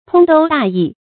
通都大邑 注音： ㄊㄨㄙ ㄉㄨ ㄉㄚˋ ㄧˋ 讀音讀法： 意思解釋： 都：都市；邑：城。四通八達的大都會、大城市。